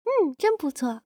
鼓掌.wav
人声采集素材/人物休闲/鼓掌.wav